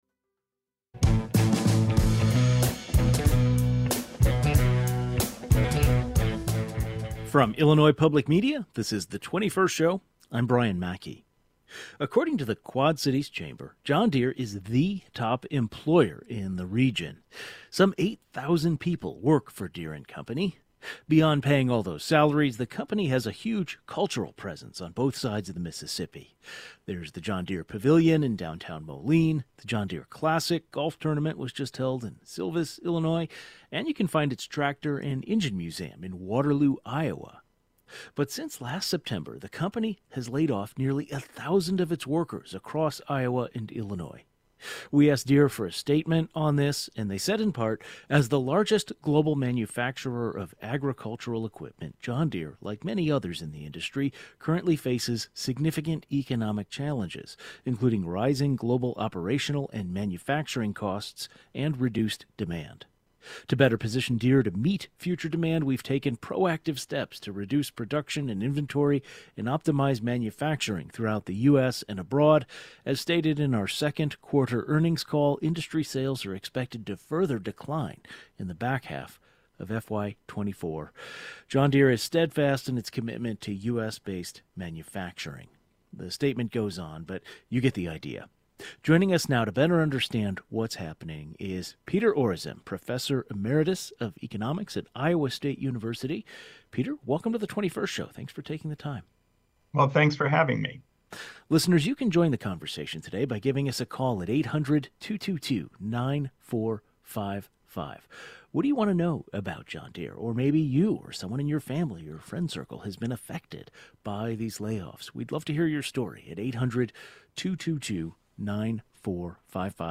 Today, we are joined by an economics professor to understand what is happening and why.